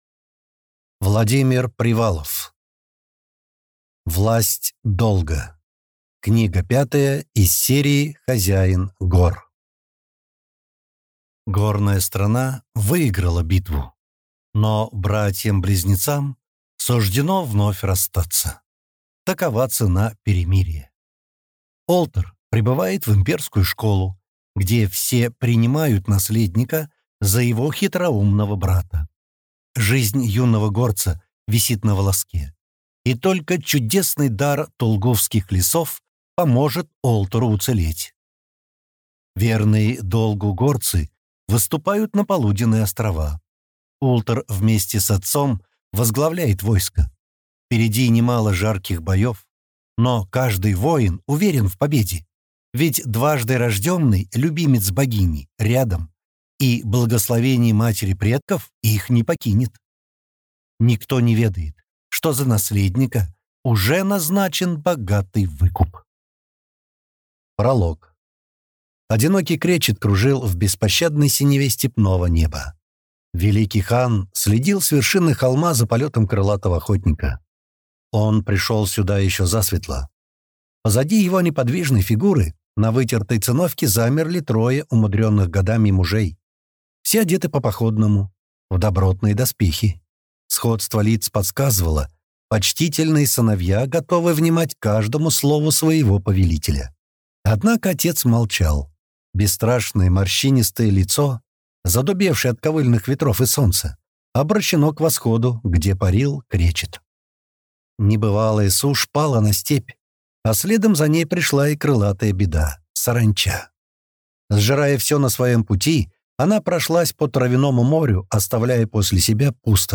Аудиокнига Власть долга | Библиотека аудиокниг